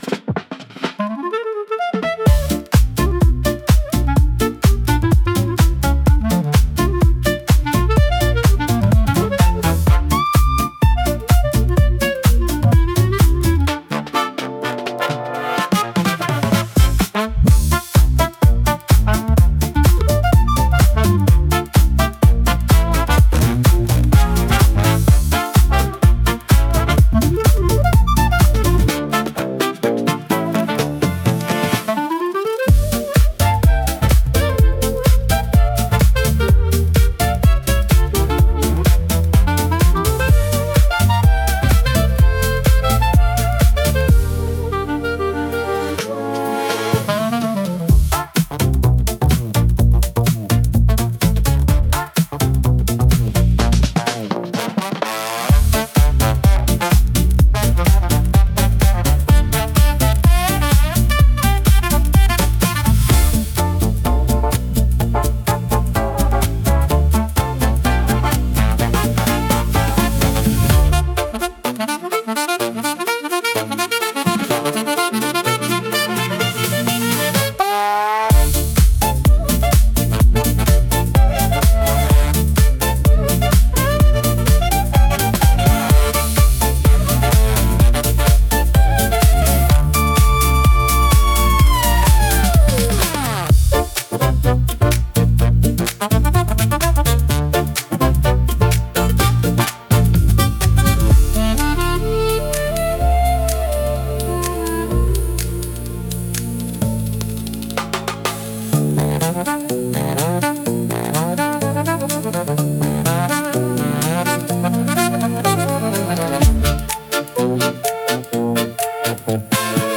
イメージ：インスト,エレクトロ・スゥイング,ジャズ
インストゥルメンタル（instrumental）